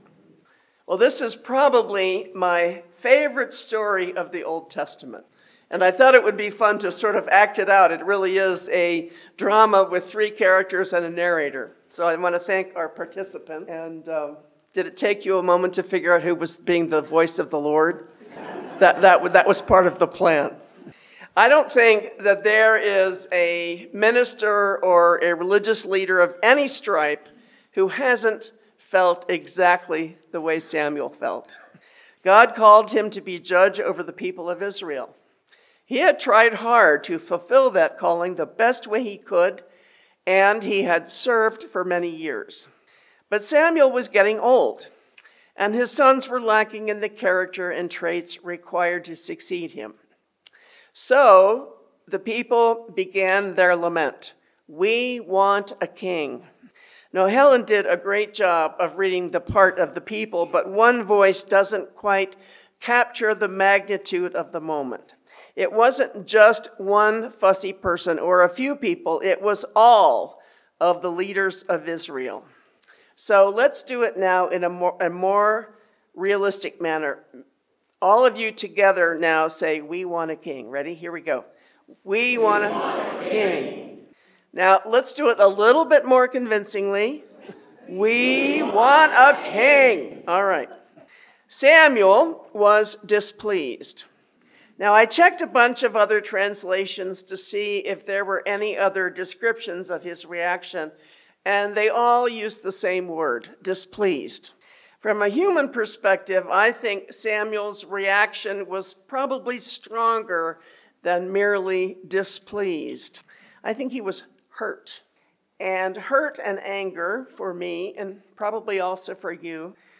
Recent Services and Sermons